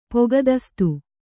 ♪ pogadastu